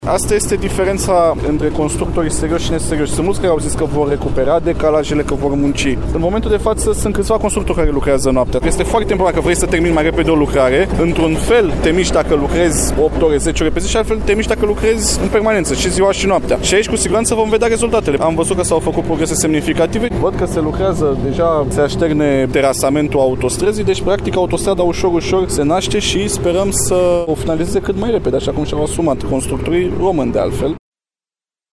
Ministrul Transporturilor, Răzvan Cuc: